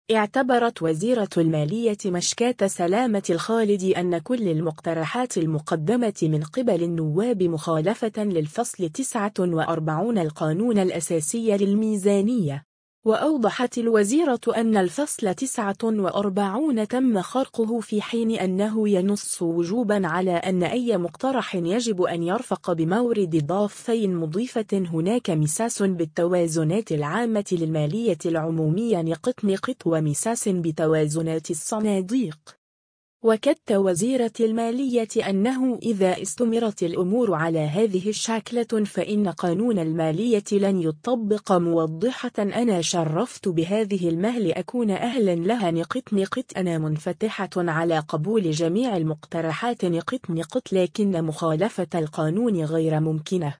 وزيرة المالية تُحذّر : “قانون المالية لن يُطبق..إذا تواصلت الأمور على هذه الشاكلة” [فيديو]